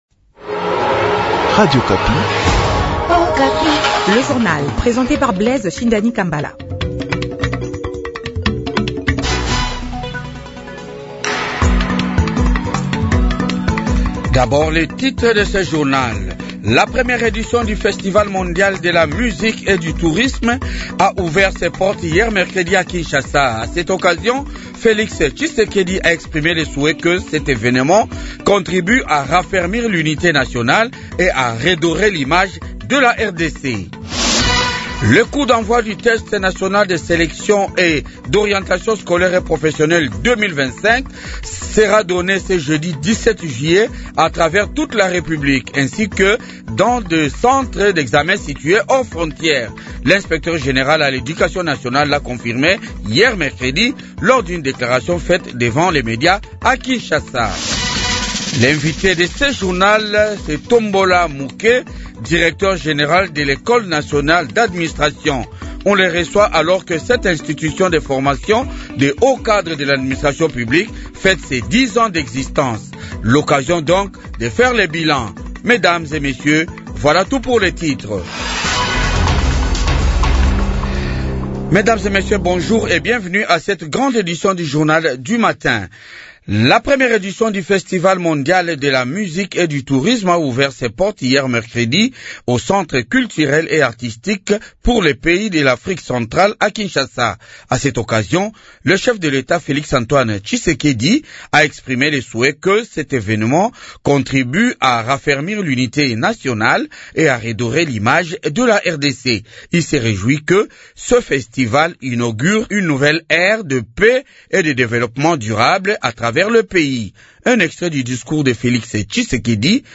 Journal Francais matin